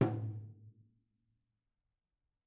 TomH_HitS_v3_rr2_Mid.mp3